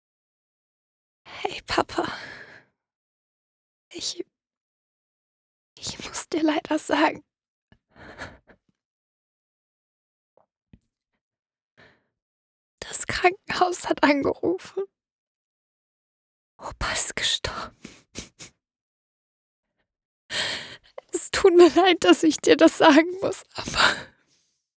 Sie muss ihrem Vater am Telefon sagen, das ihr Opa gestorben ist und bricht in Tränen aus.